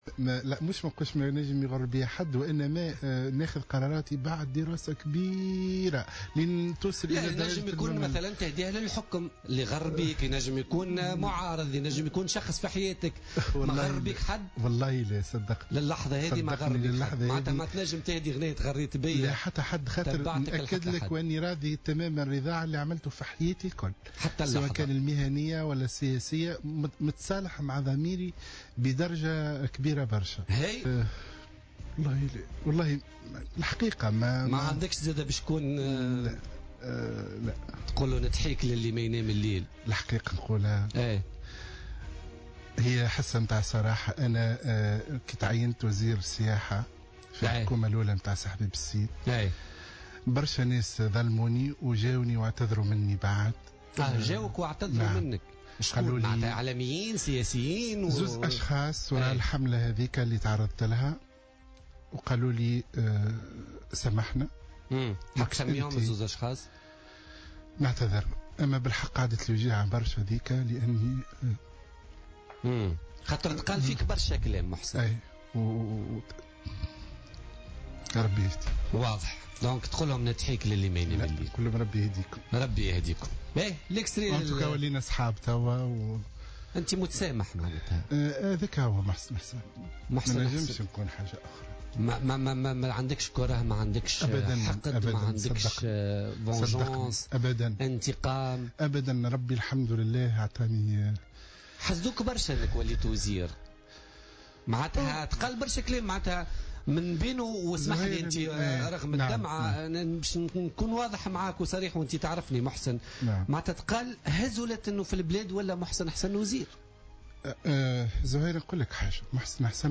L'ex-leader de l'union Populaire libre (UPL), Mohsen Hassan était l'invité